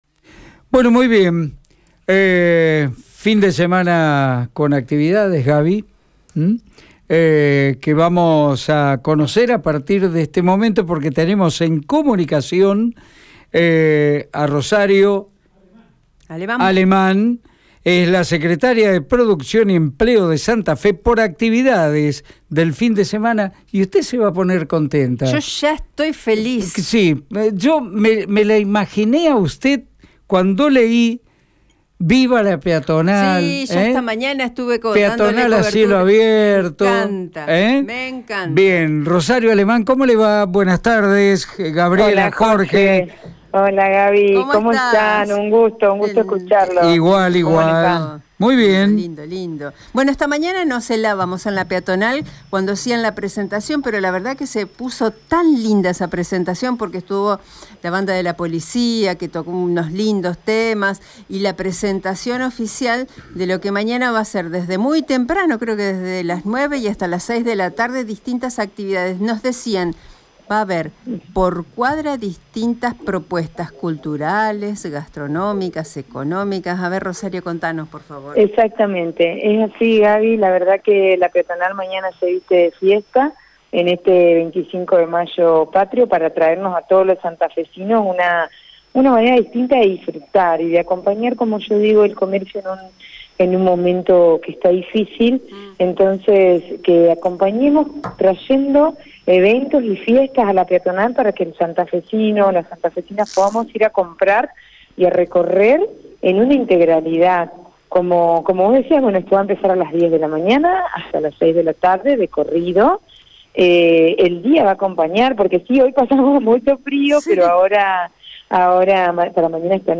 Escucha la palabra de Rosario Aleman, Secretaria de Producción y Empleo de Santa Fe, en Radio EME: